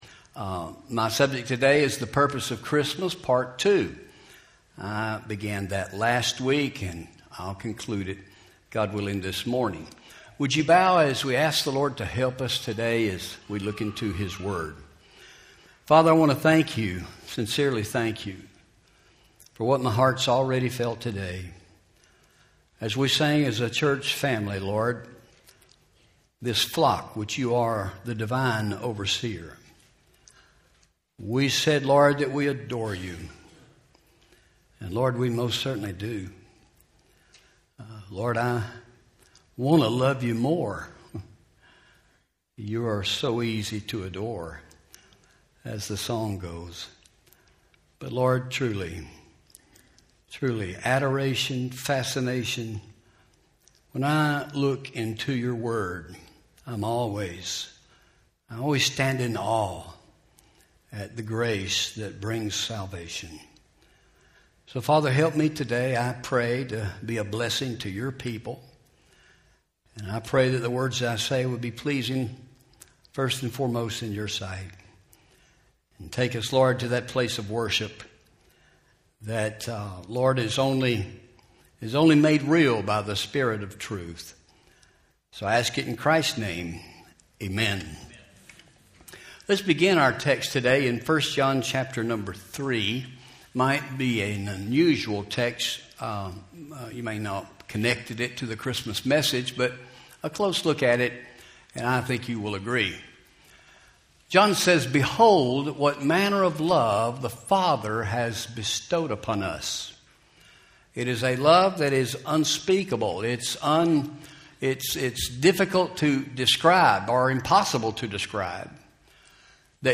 Home › Sermons › The Purpose Of Christmas Part 2